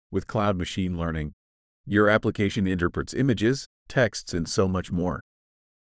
Google TTS example output
Integrated seamlessly with Google services, this tool provides a robust solution for converting text into natural-sounding speech.